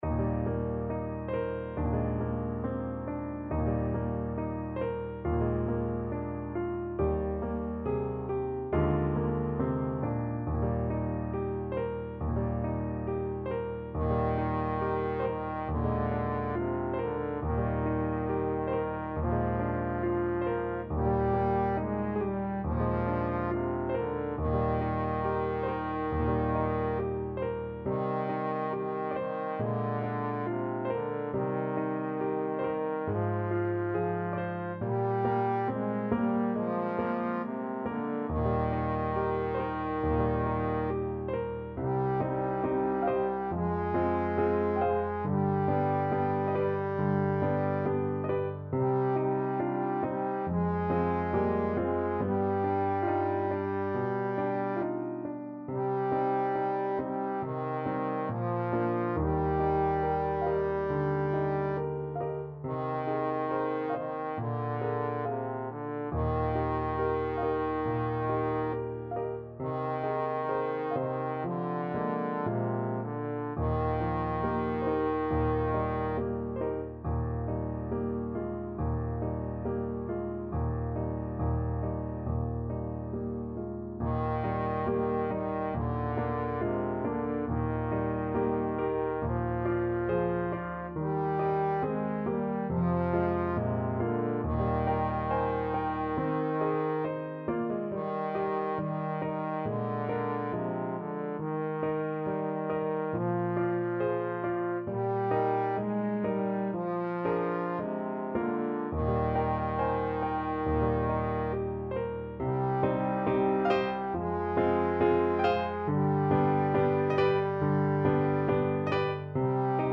~ = 69 Andante tranquillo
Classical (View more Classical Trombone Music)